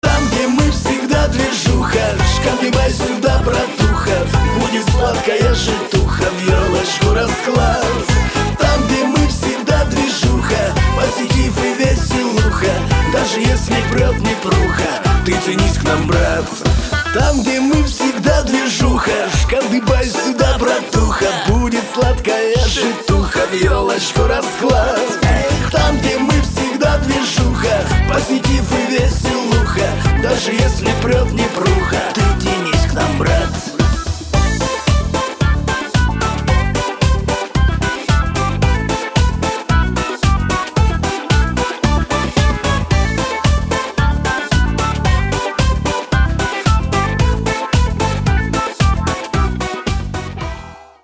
• Качество: 320, Stereo
позитивные
мужской вокал
веселые
русский шансон
хриплый голос